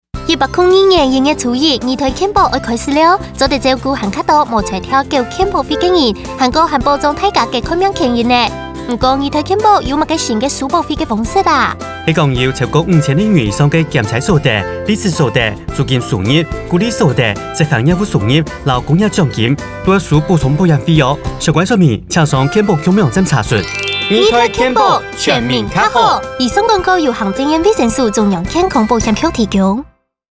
廣播